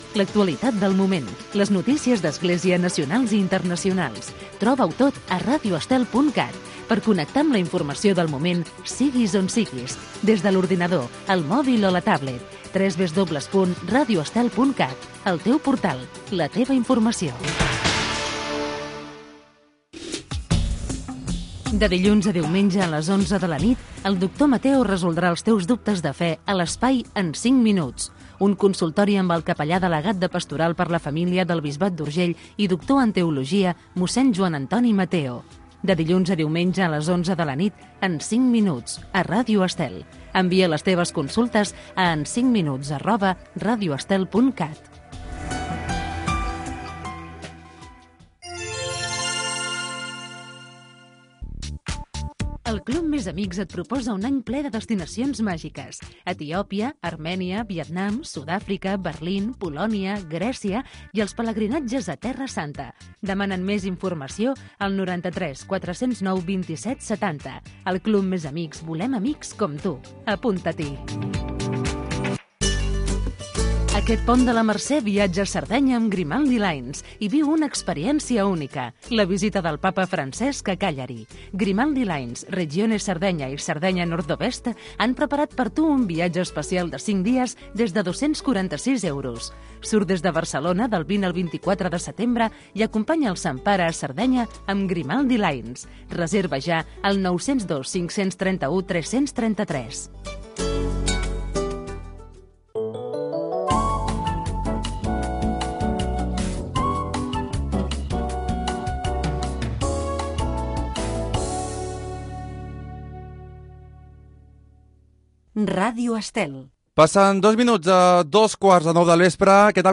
Adreça web de l'emissora, promoció del programa "En cinc minuts", publicitat, indicatiu de l'emissora, hora, salutació, careta del programa, informació de l'Eurobasket d'Eslovènia, noms propis de la jornada
Esportiu